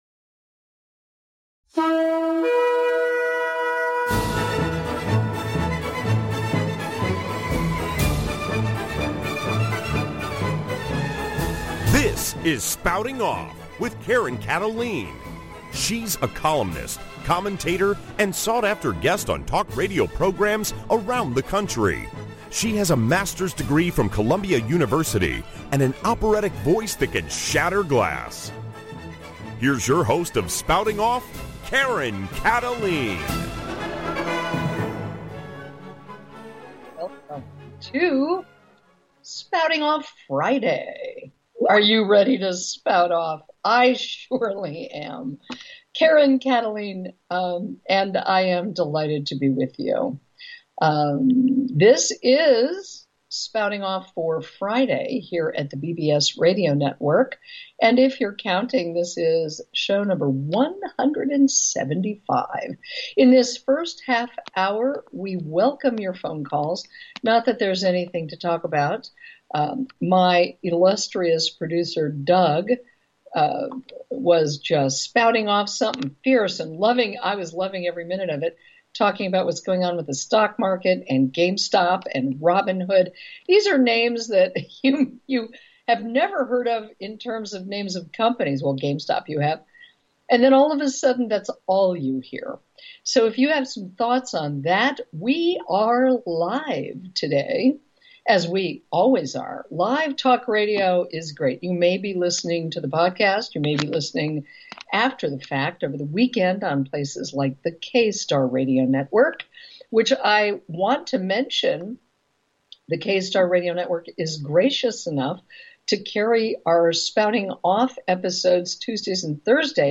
Spouting Off Talk Show